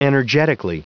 Prononciation du mot energetically en anglais (fichier audio)
energetically.wav